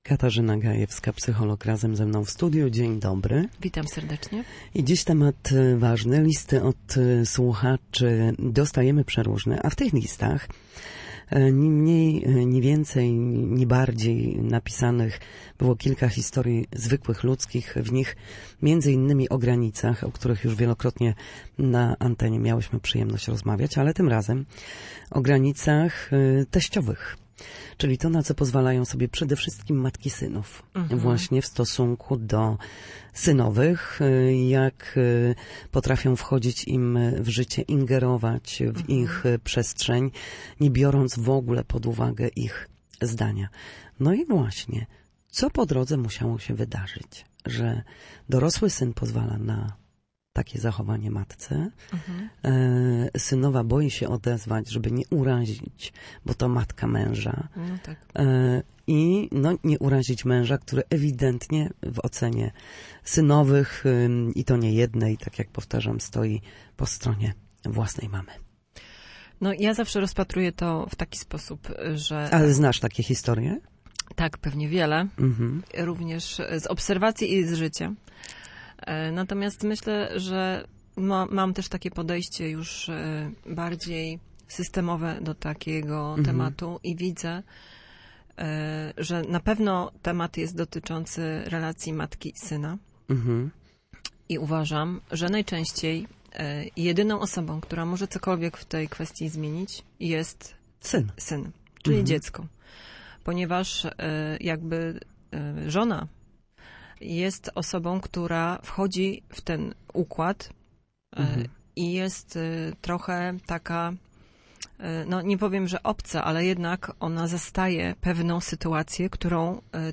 Jak radzić sobie w trudnych sytuacjach rodzinnych? Między innymi na temat relacji między synową a teściową rozmawialiśmy w audycji „Na zdrowie”.